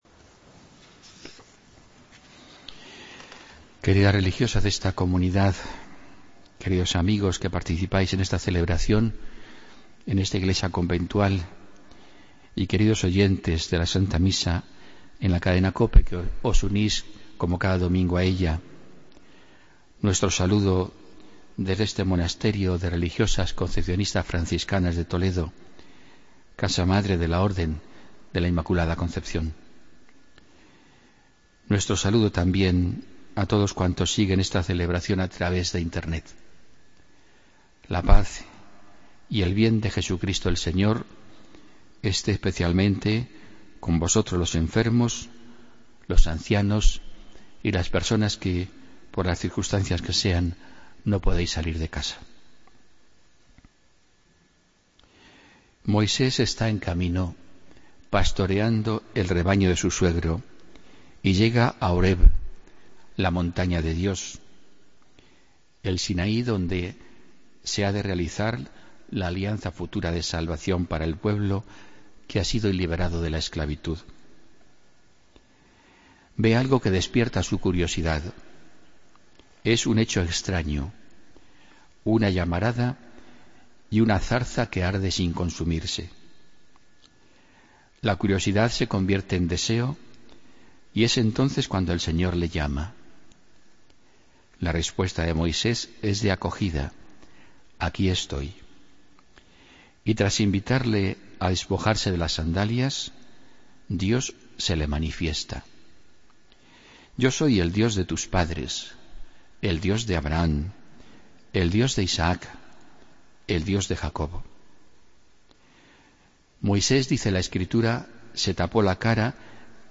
Homilía del domingo 28 de febrero de 2016